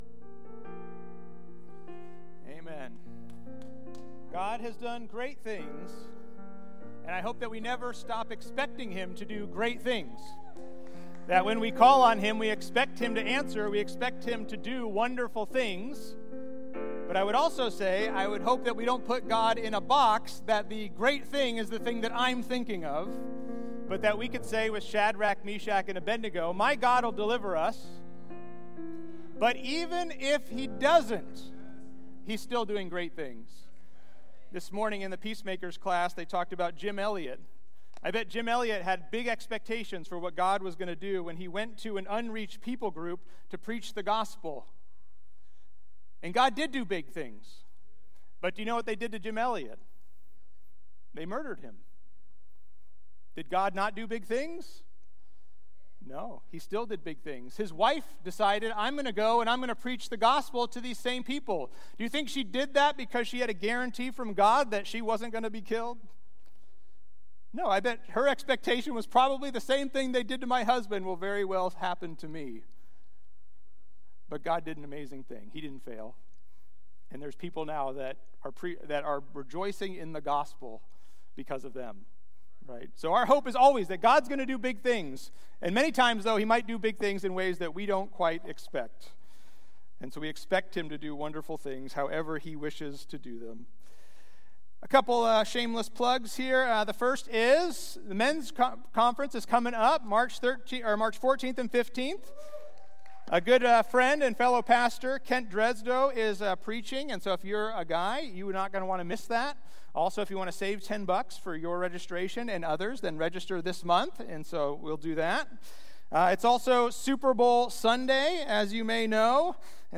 The Mess We're In And The Hope God Offers Valley Bible Church Sermons (VBC) - Hercules, CA podcast